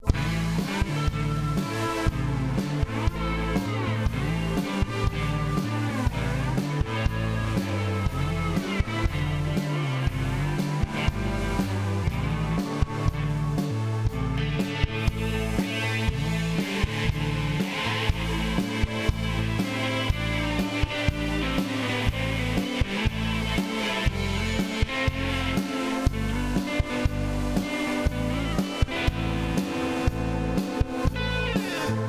Captured with a Lucid 88192
Full Mix Heavy Compression • 15 db RMS Gain Reduction  • Threshold: -15 • Ratio: 10:1
Full Mix - Heavy Compression - Stock DBX 560a